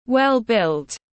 Thân hình cân đối tiếng anh gọi là well-built, phiên âm tiếng anh đọc là /ˌwel ˈbɪlt/ .